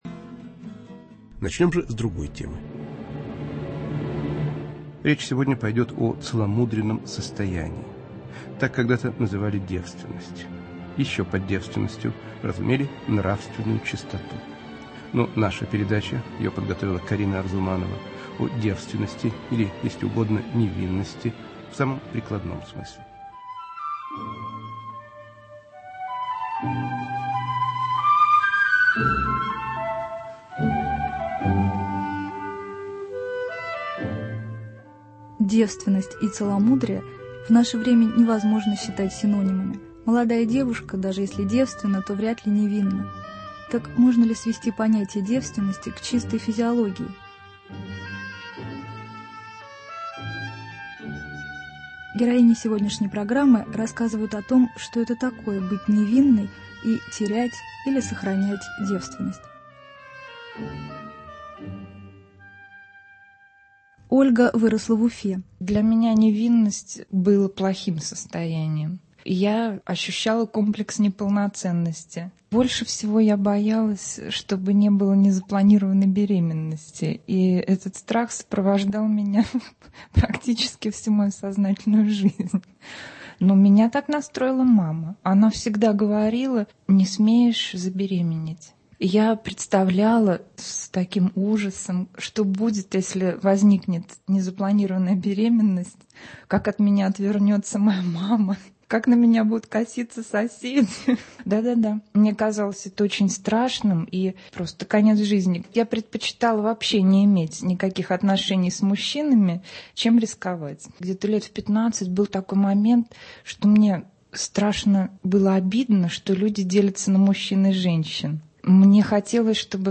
В передаче "Невинность" молодые женщины разных национальностей и вероисповеданий обсуждают различные аспекты сексуального воспитания и поведения.